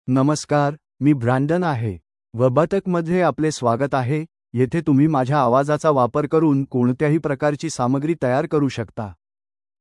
Brandon — Male Marathi AI voice
Brandon is a male AI voice for Marathi (India).
Voice sample
Listen to Brandon's male Marathi voice.
Male
Brandon delivers clear pronunciation with authentic India Marathi intonation, making your content sound professionally produced.